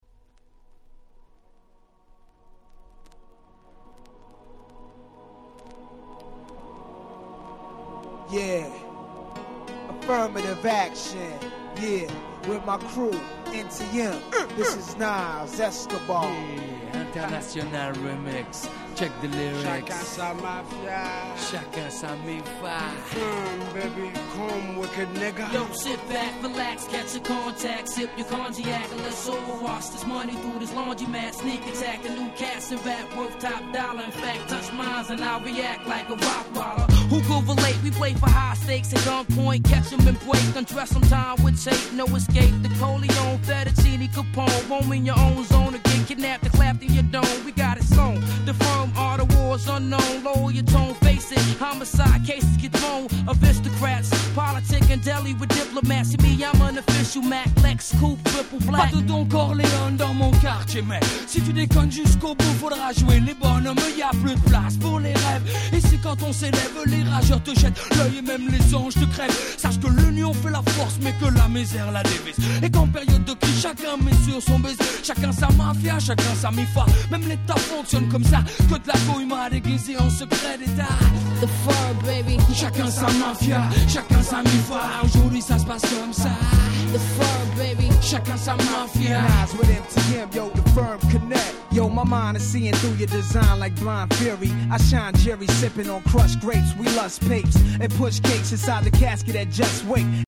97' Smash Hit Hip Hop !!
90's Boom Bap ブーンバップ